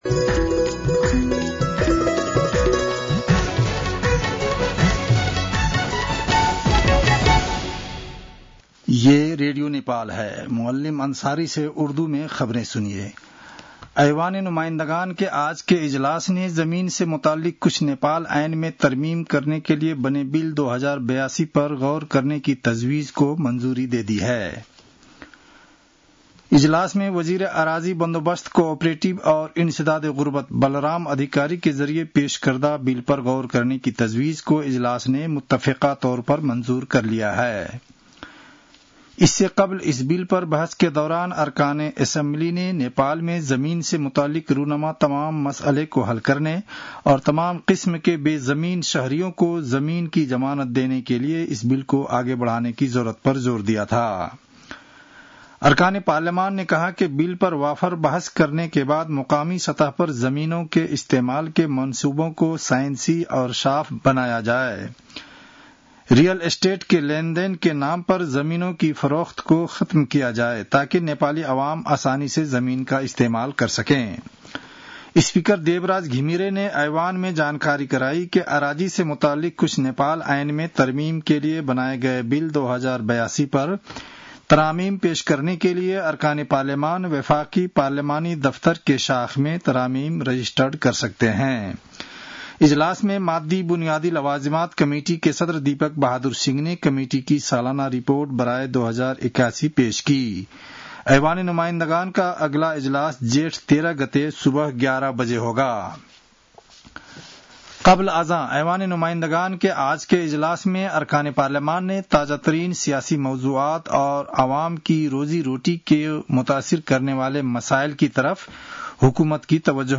उर्दु भाषामा समाचार : ६ जेठ , २०८२